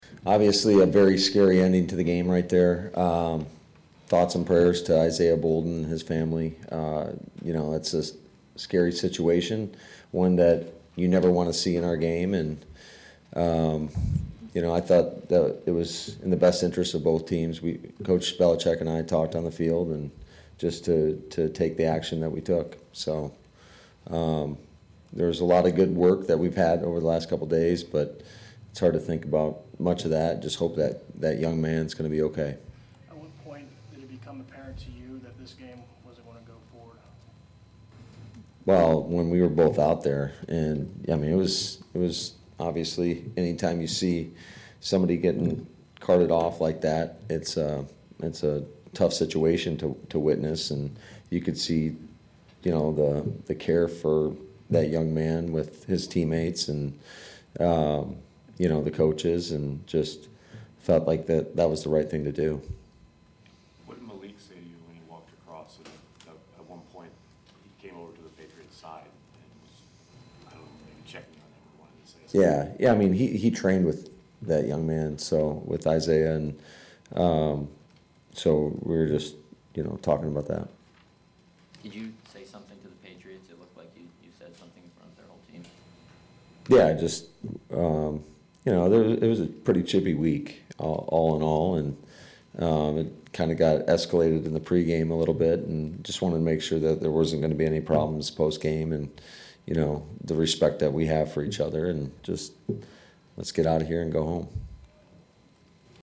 LaFleur spent the first two minutes of his press conference discussing the sobering finish to the night: